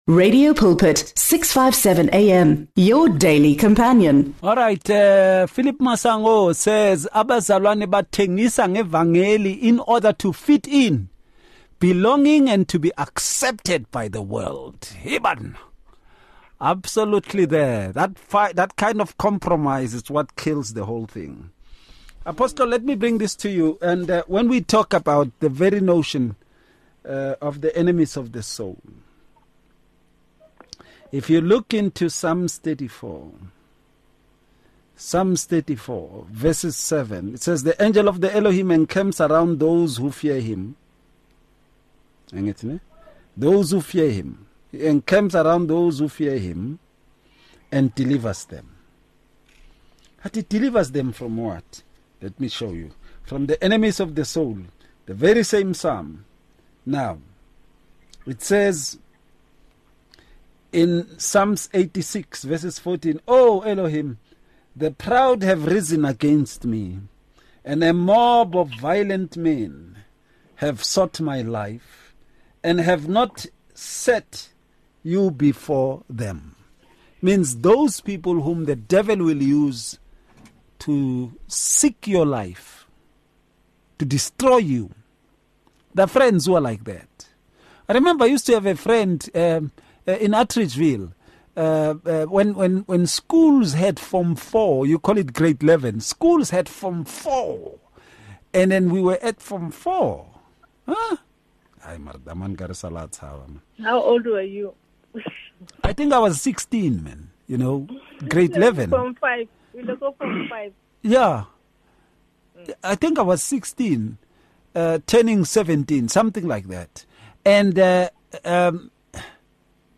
ENGLISH SOUTH AFRICA